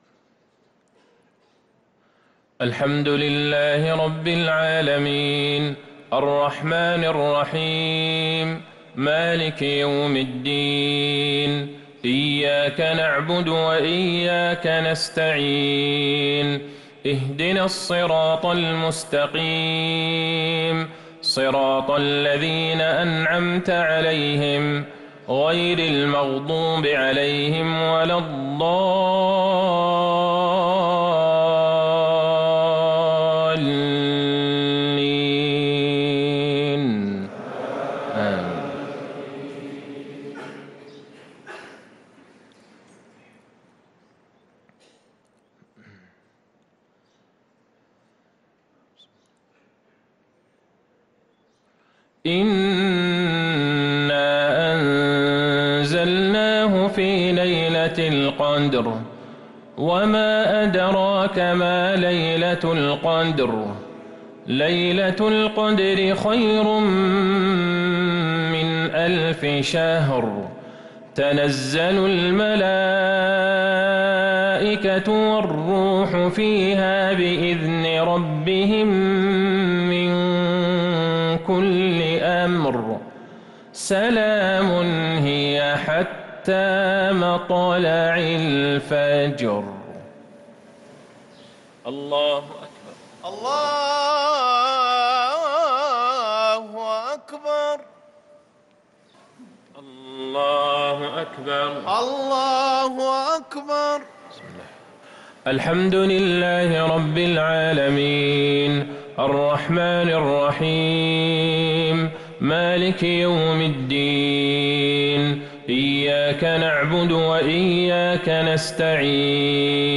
صلاة المغرب للقارئ عبدالله البعيجان 5 جمادي الأول 1445 هـ
تِلَاوَات الْحَرَمَيْن .